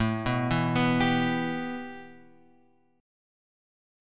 A 7th Suspended 2nd